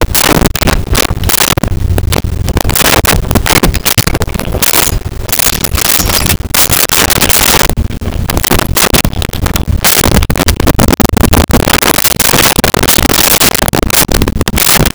Keys Movement At Door
Keys Movement At Door.wav